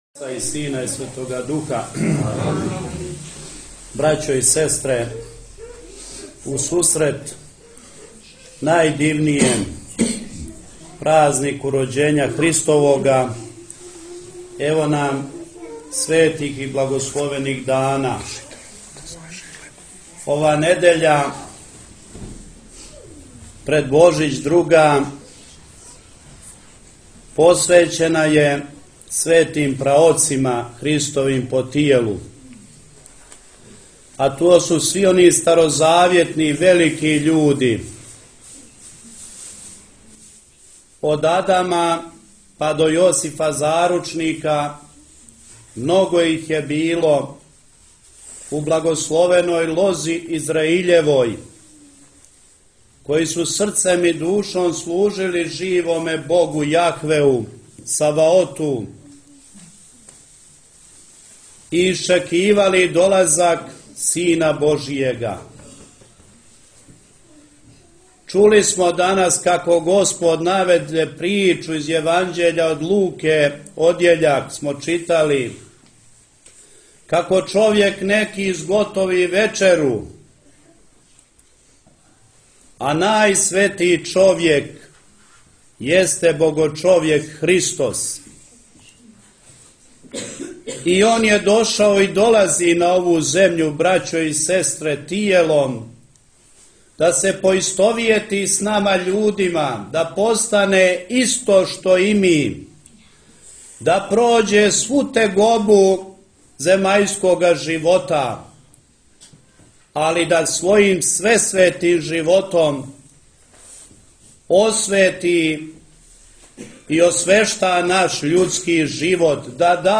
Mitropolit Joanikije bogoslužio u crkvi Svetog Spiridona u Đenovićima